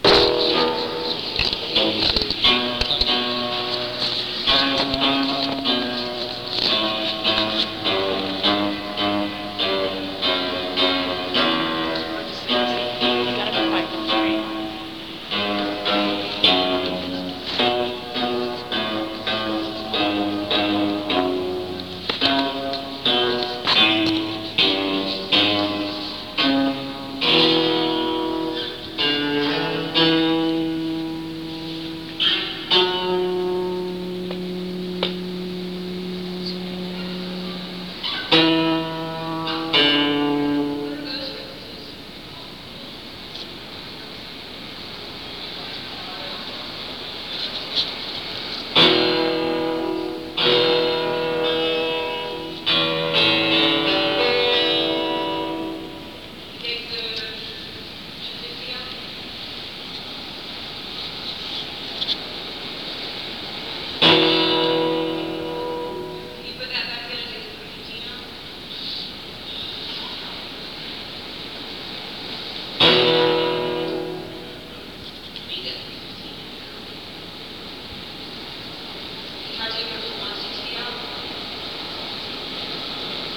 (band show)
02. tuning (soundcheck) (1:23)